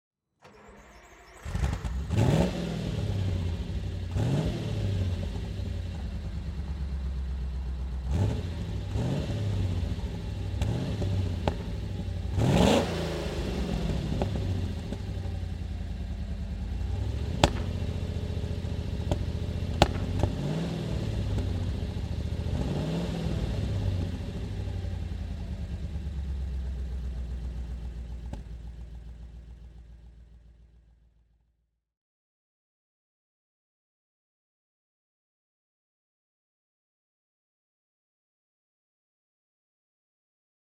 To give you an impression of the variety, we have selected ten different engine sounds.
Maserati Quattroporte I (1965) - Starting and idling
Maserati_QP_1965.mp3